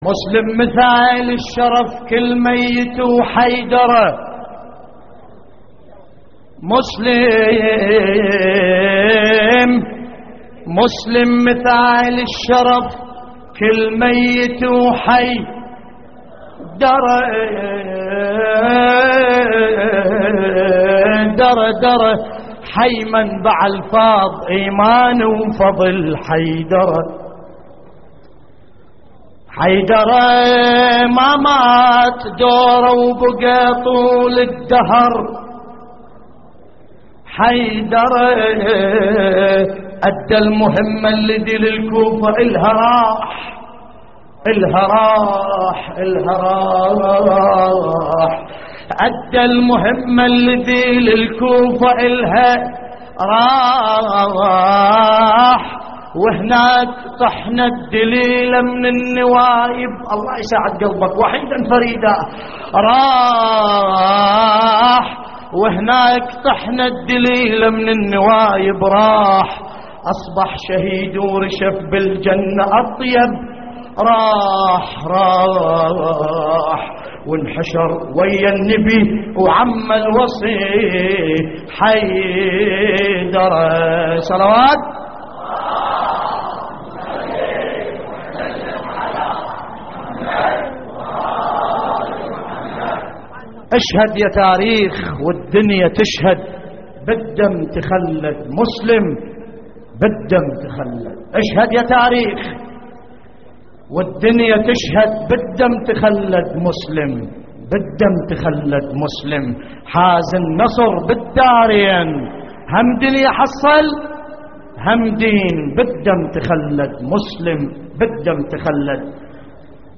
تحميل : إشهد يا تاريخ والدنيا تشهد بالدم تخلد / الرادود باسم الكربلائي / اللطميات الحسينية / موقع يا حسين